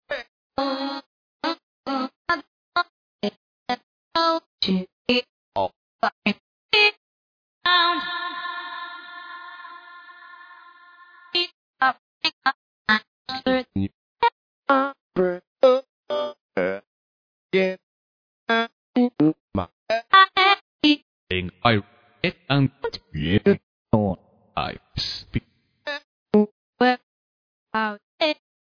(the prelisten files are in a lower quality than the actual packs)
flph_voxvocalcuts3_showcase.mp3